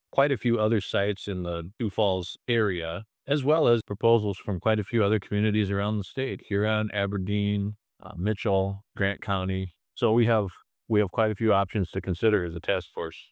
Committee chair, Lieutenant Governor Tony Venhuizen, says the group will have ten sites to choose from – half of them in the Sioux Falls area.